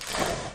Ship-getting-hit.wav